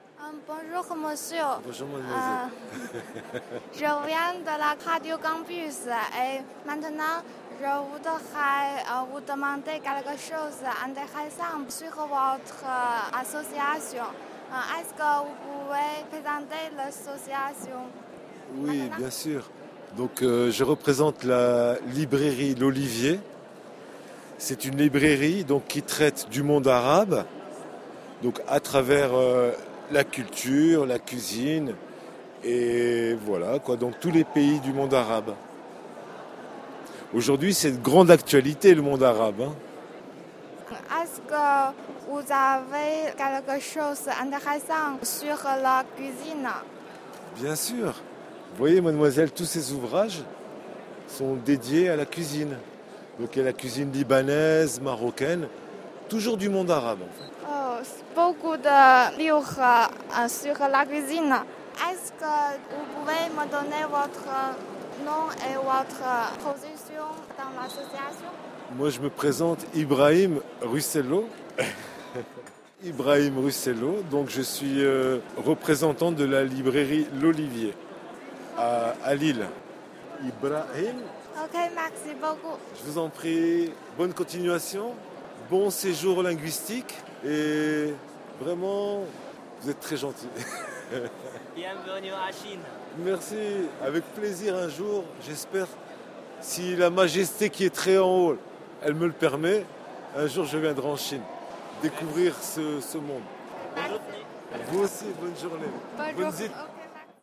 Cette 7ème édition du festival des langues a eu lieu les 8 et 9 avril 2011 à la Chambre de Commerce et d'Industrie Grand Lille
L'équipe était constituée d'étudiants chinois de Lille 1